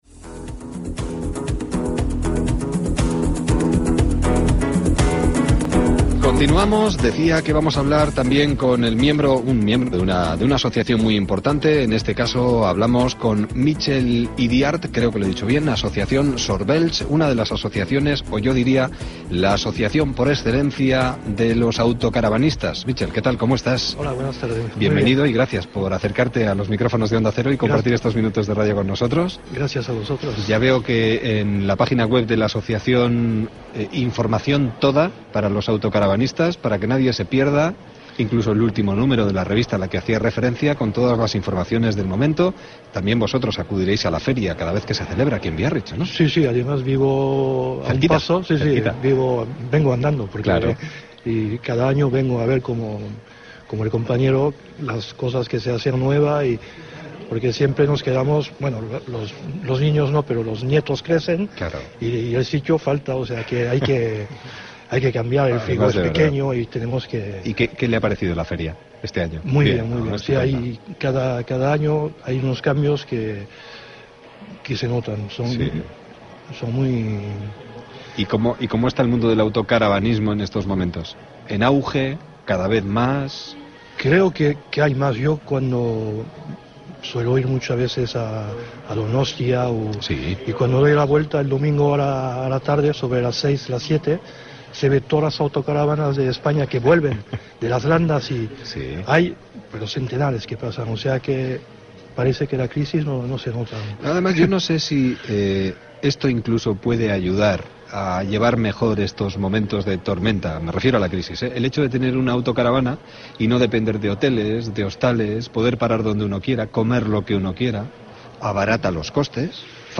Entrevista en Onda Cero
Con motivo de la Feria de autocaravanas de Biarritz y dentro de un programa más amplio sobre la feria y el autocaravanismo en general, la emisora de radio Onda Cero realizó el pasado jueves 8 de marzo una entrevista a un socio del club.